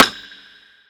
Rimshe_3.wav